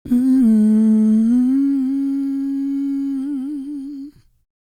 E-CROON P321.wav